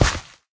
gravel3.ogg